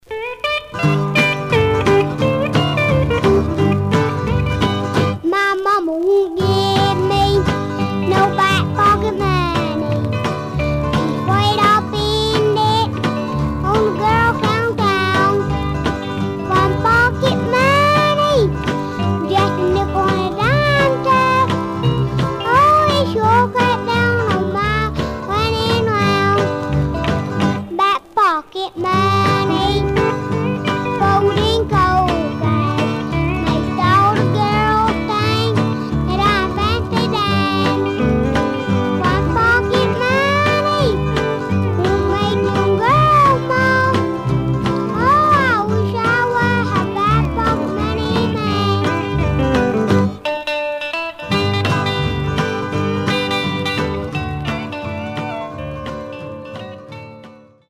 Surface noise/wear Stereo/mono Mono
Rockabilly